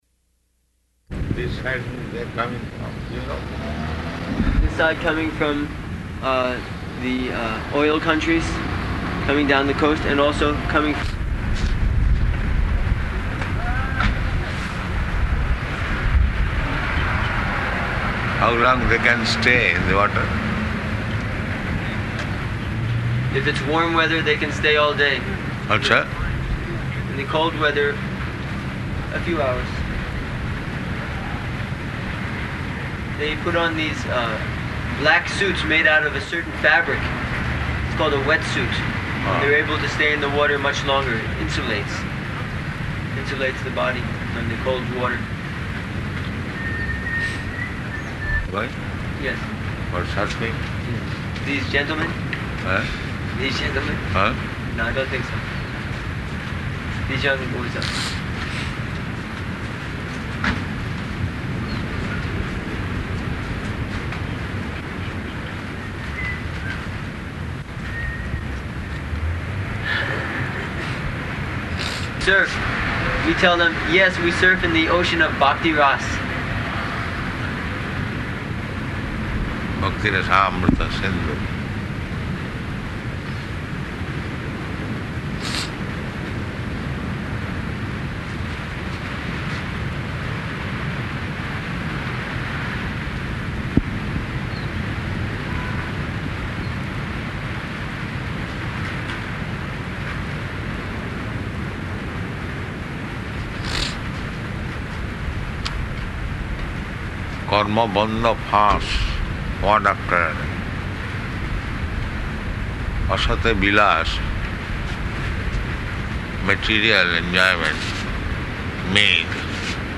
Morning Walk --:-- --:-- Type: Walk Dated: October 13th 1975 Location: Durban Audio file: 751013MW.DUR.mp3 Prabhupāda: And this side, they are coming from Europe?